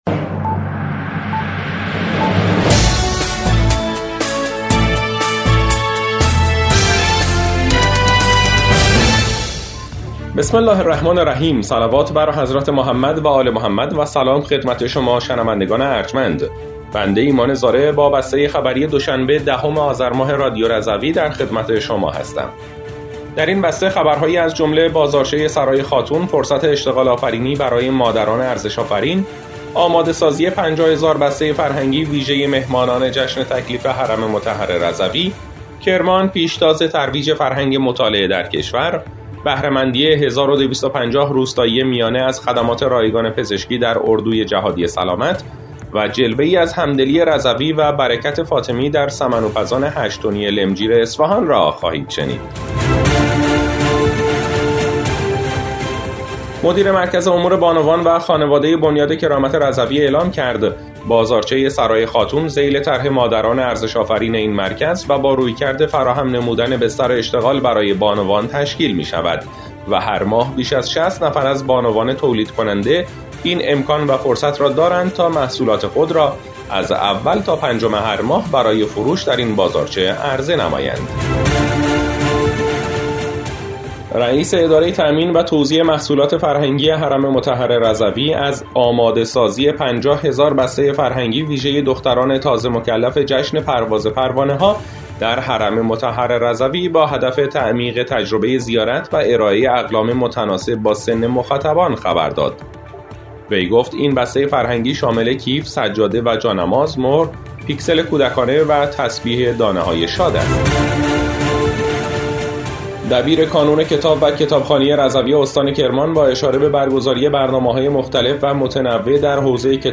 بسته خبری ۱۰ آذر ۱۴۰۴ رادیو رضوی؛